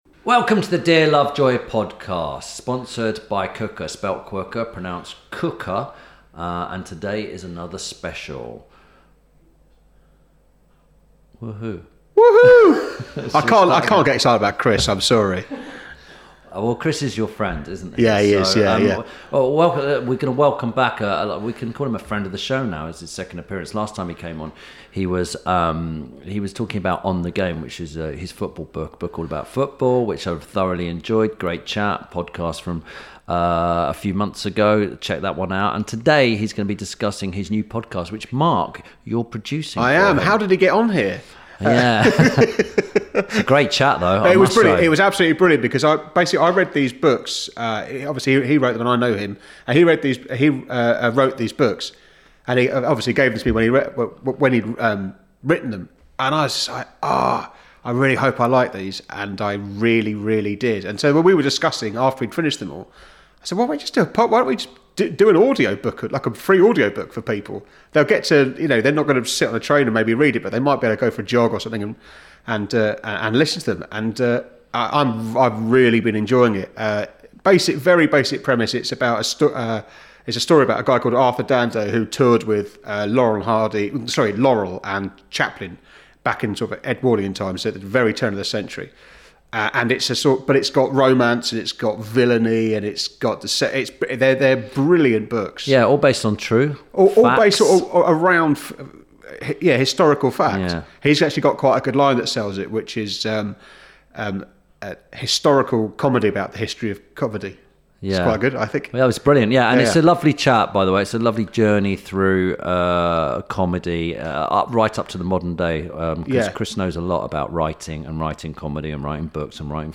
This week Tim Lovejoy talks to author and brand new podcaster Chris England. Whilst discussing his new podcast and books, Tim talks to Chris about the history of comedy, why Stan Laurel is far superior to Charlie Chaplin and the problem with VAR in football.